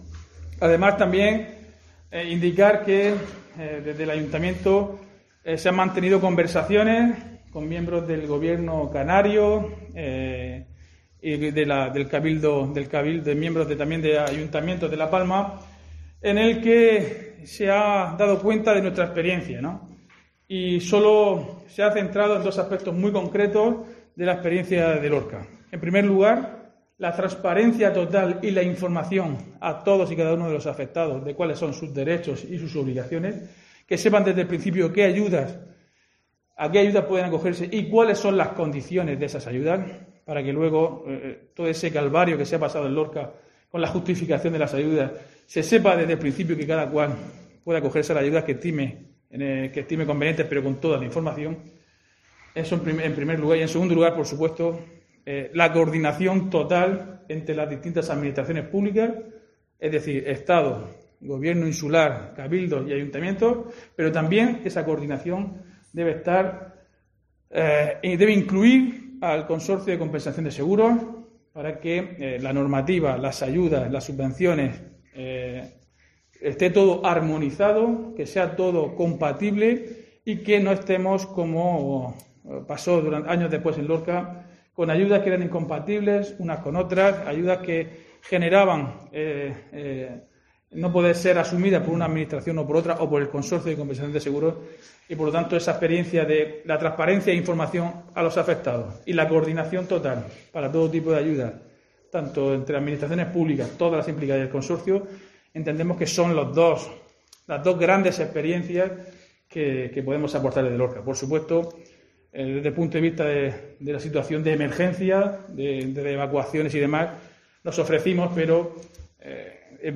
Diego José Mateos, alcalde de Lorca sobre donación La Palma